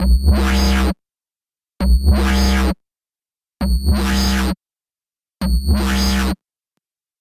Step 3 – EQ
Our sound is now taking shape, but is sounding a little harsh.
I’ve made cuts at about 500 Hz and 2 kHz, as well as rolling off the sub bass from about 60 Hz – in this before and after you can hear how these cuts have removed some of the harsher and muddy tones from the sound.
The difference is subtle but you should be able to hear a slightly brighter, more refined tone.